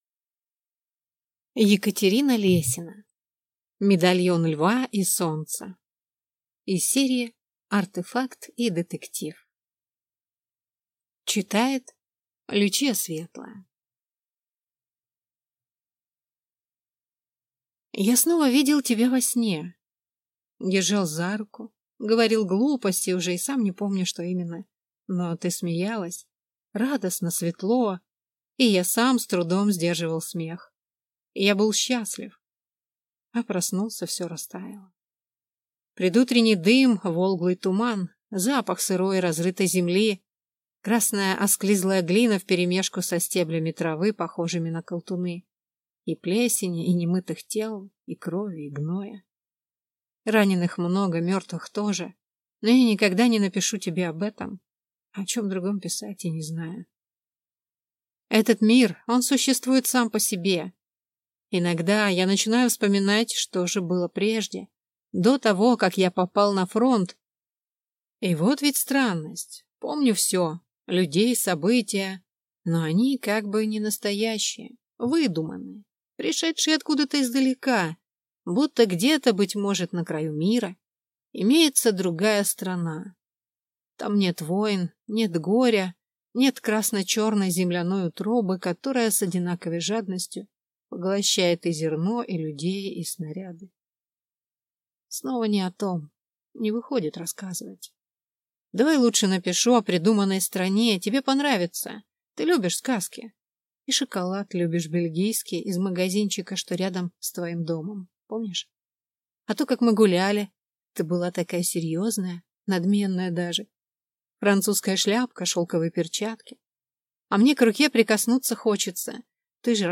Аудиокнига Медальон льва и солнца | Библиотека аудиокниг